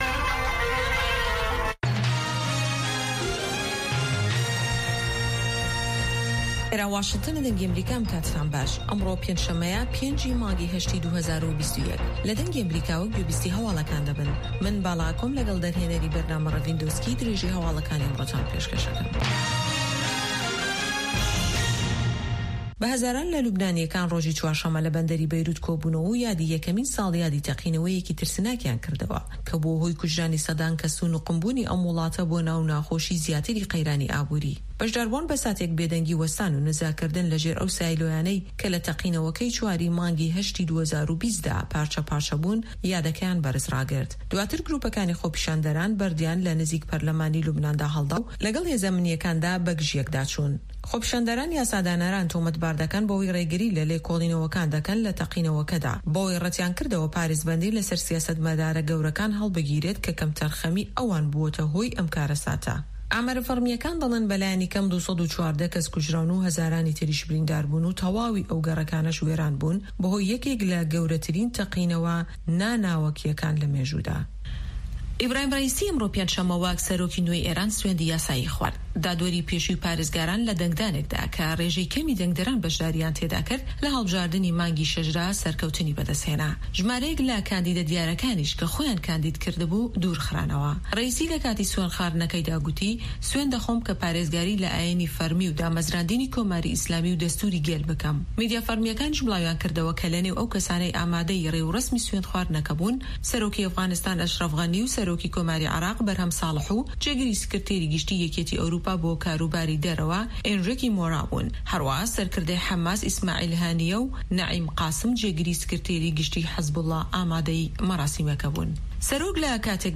Nûçeyên 3’yê paşnîvro
Nûçeyên Cîhanê ji Dengê Amerîka